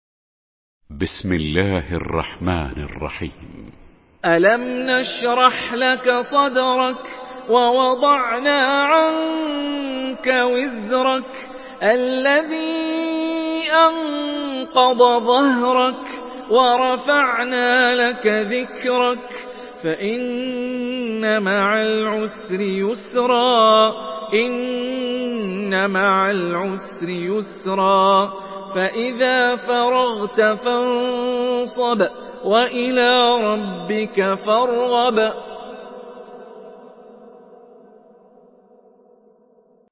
Surah Ash-Sharh MP3 in the Voice of Hani Rifai in Hafs Narration
Surah Ash-Sharh MP3 by Hani Rifai in Hafs An Asim narration.
Murattal Hafs An Asim